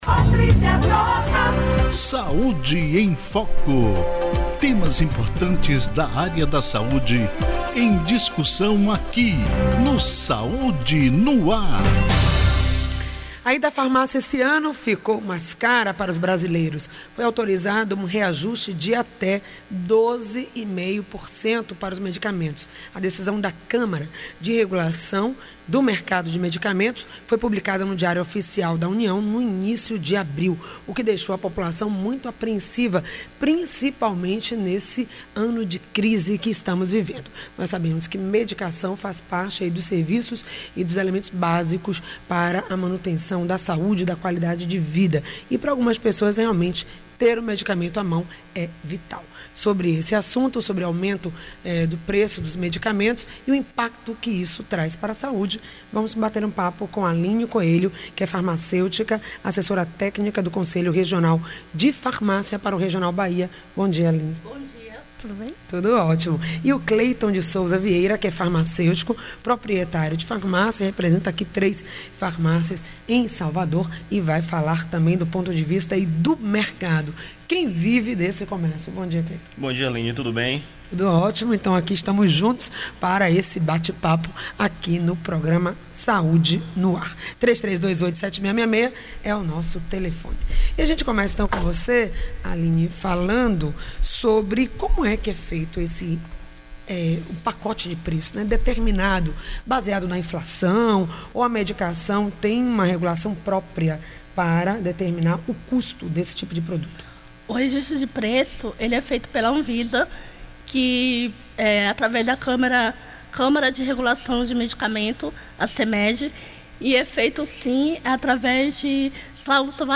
O programa transmitido pela rádio AM 840(em 29.04.16 das 8h às 9h) .